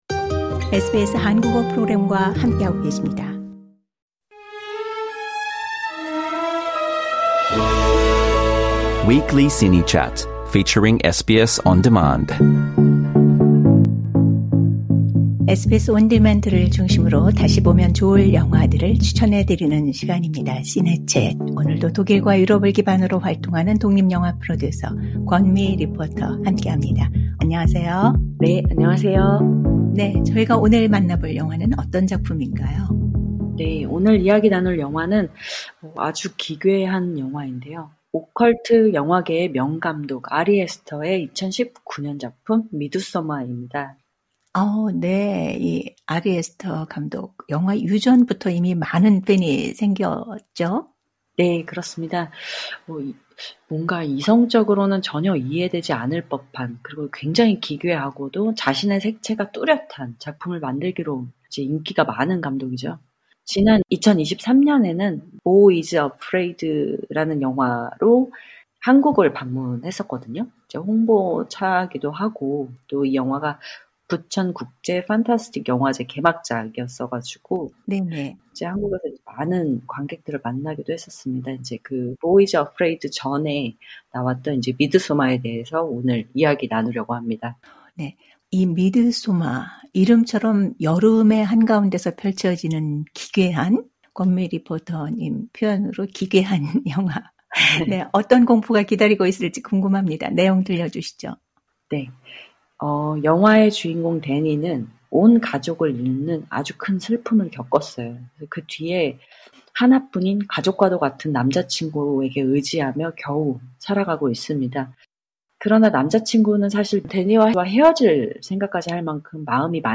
Trailer Audio Clip 그래서 이제 이 남자친구는 데니와 헤어지지도 못하고 또 여행에 대해서 미리 말하지 않아 가지고 이제 내심 미안하기도 하고 굉장히 우유부단한데 그래서 결국은 데니한테 동행을 제안하죠.